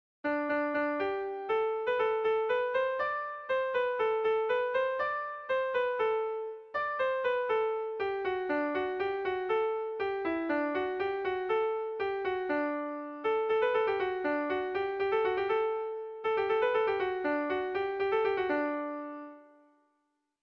Erromantzea
ABD